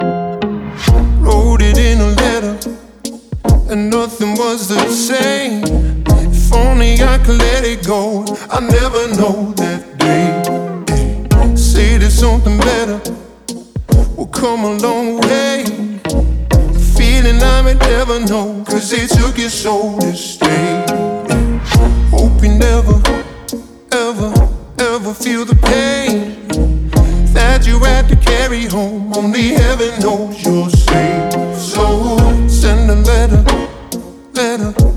Скачать припев, мелодию нарезки
Blues